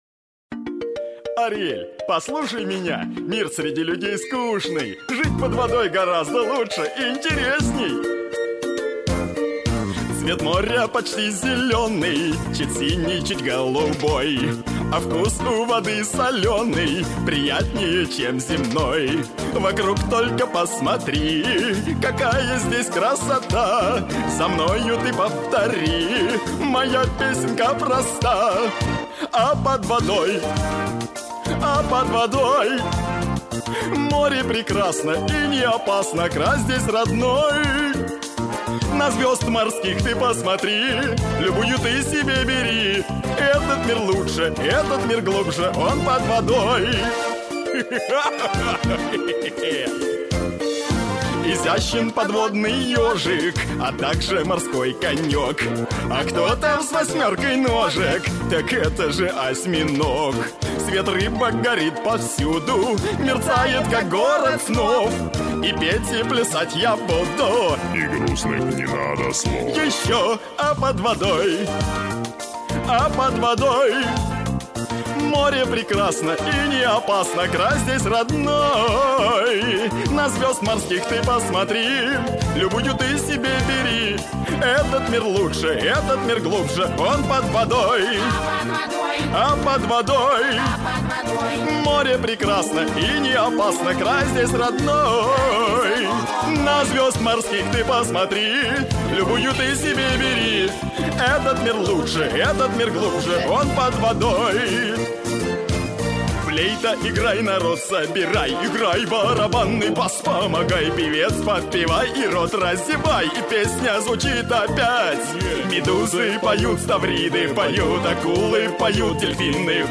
• Качество: Хорошее
• Категория: Детские песни
🎶 Детские песни / Песни из мультфильмов